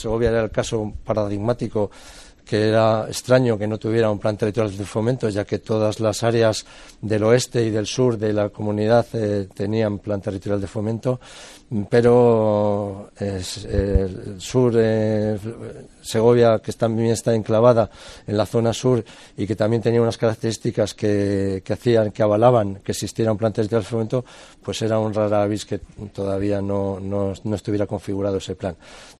Mariano Veganzones, consejero de Industria, Comercio y Empleo